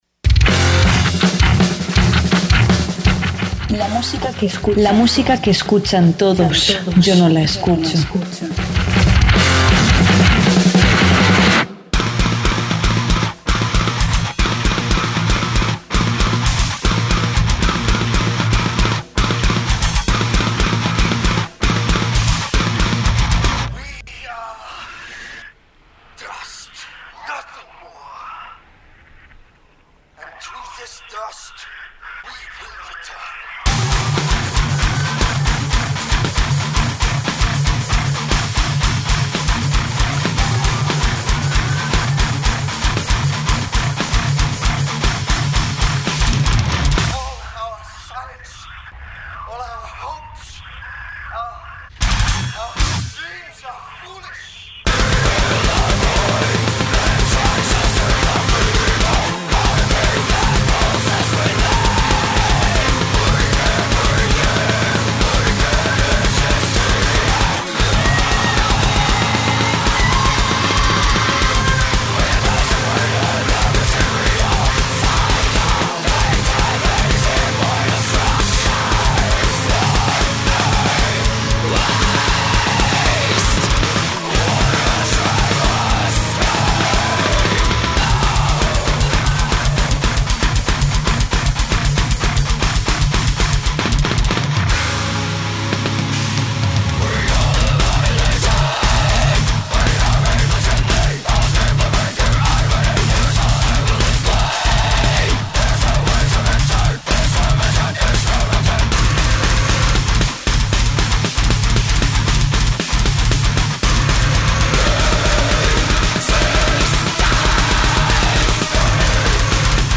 tech death metal
rock